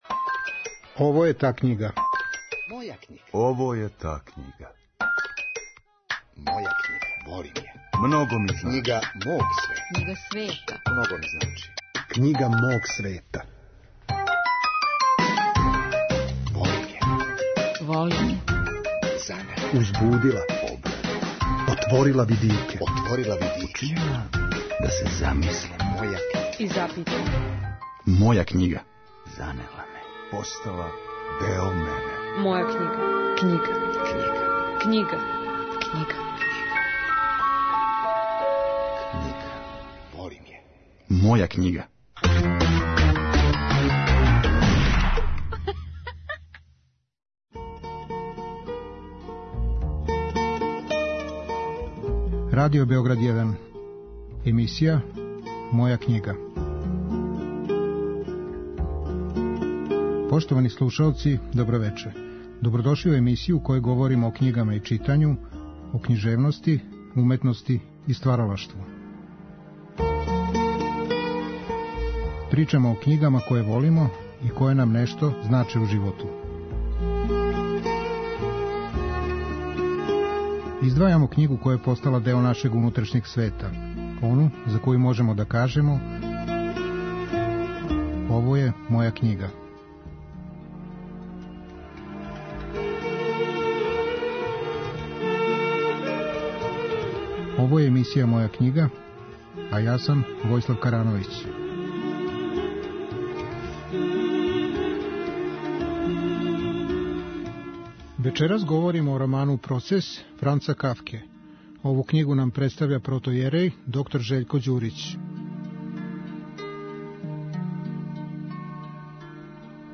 Наш саговорник говори о томе како доживљава Кафкина дела, пре свих роман ''Процес'', шта му је у њему занимљиво и подстицајно. Биће речи о појединцу изгубљеном у лавиринту бирократије, и о питању кривице које је актуализовано у роману ''Процес''. Чућемо и то у којим аспектима Кафкине загонетне прозе наш гост види боготражитељски пут.